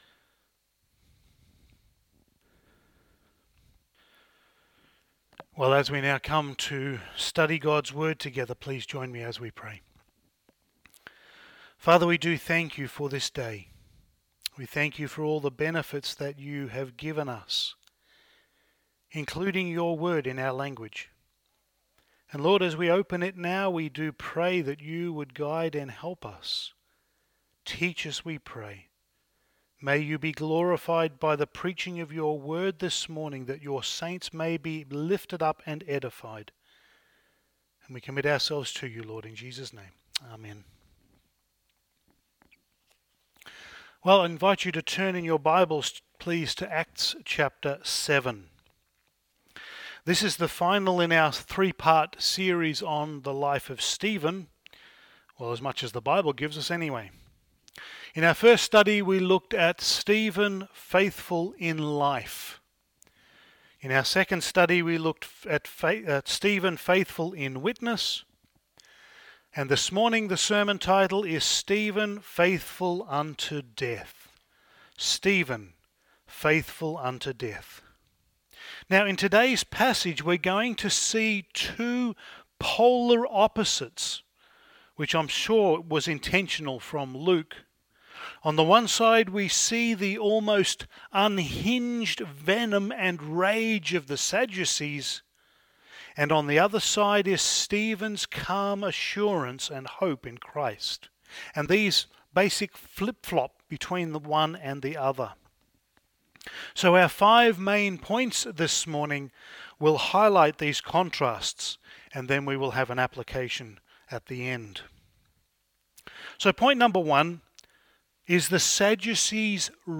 Passage: Acts 7:54-8:3 Service Type: Sunday Morning